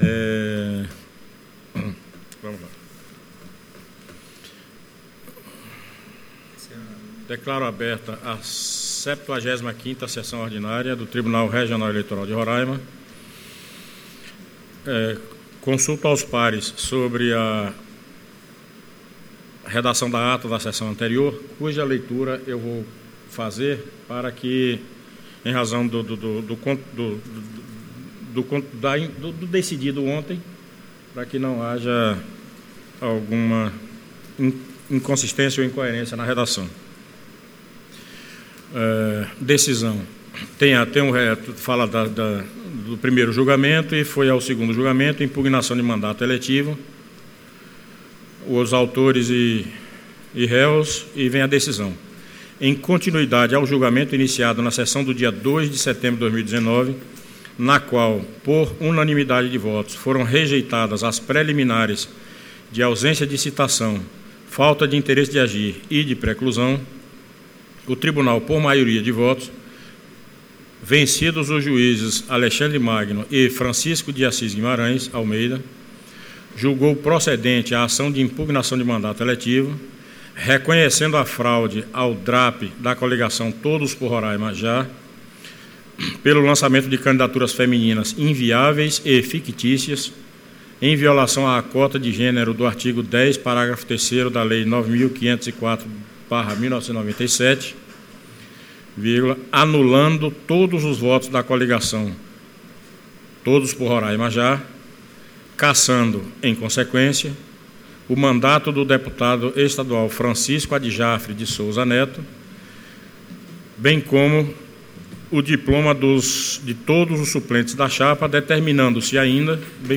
Áudio da 75ª Sessão Ordinária de 08 de outubro de 2019.